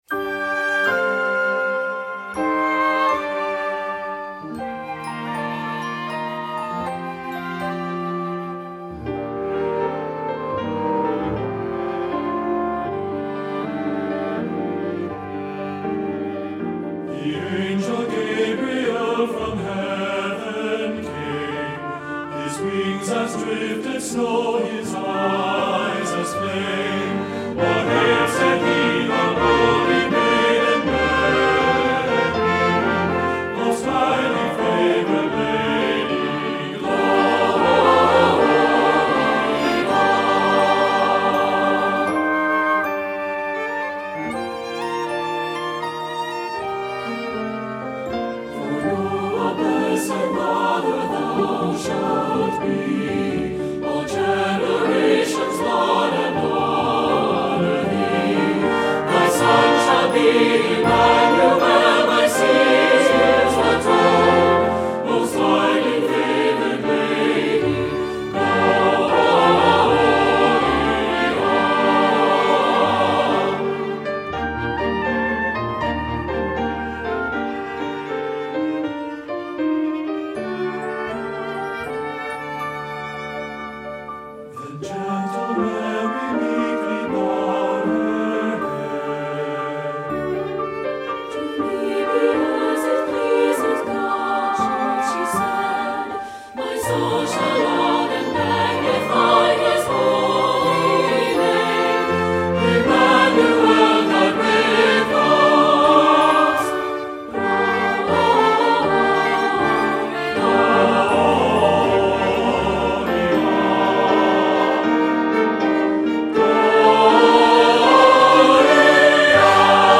Voicing: Sa/TB - 4 Parts On 2 - Rehearsal